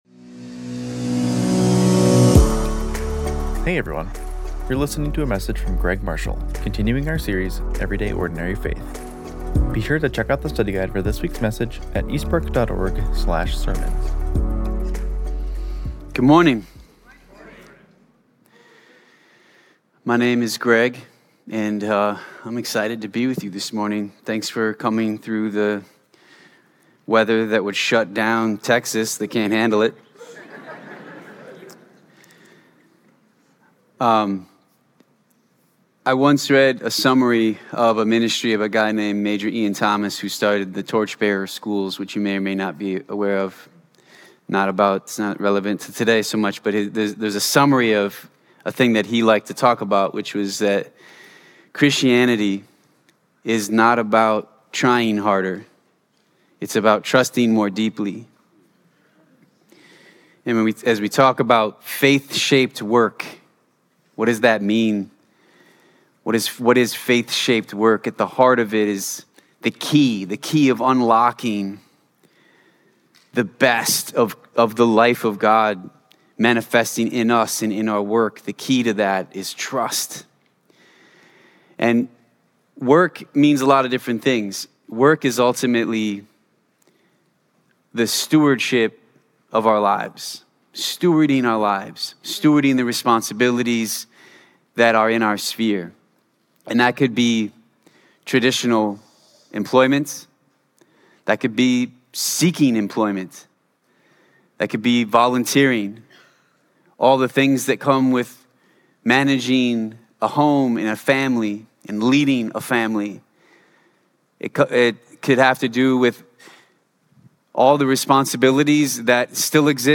Matthew 28:18-20 Sermon Outline Which word best describes your work situation: demanding, wonderful, meaningless, frustrating, satisfying, non-existent, stressful, joyful or other?